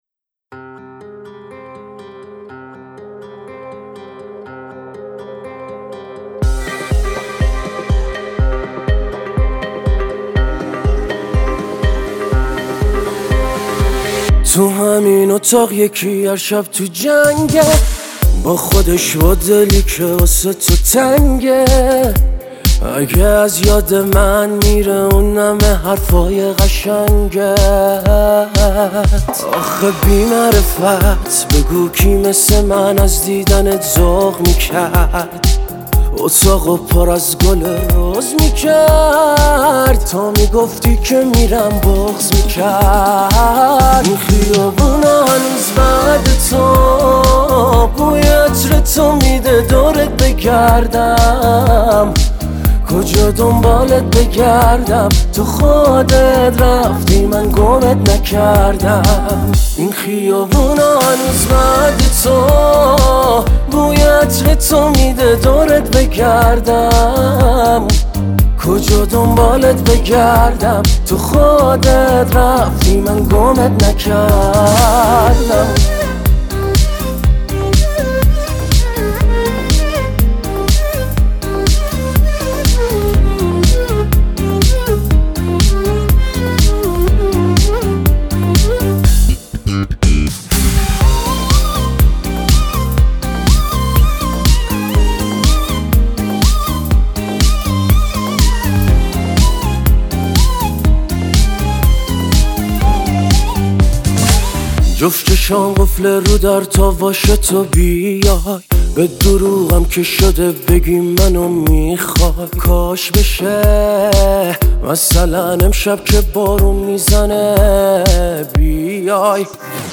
خواننده پاپ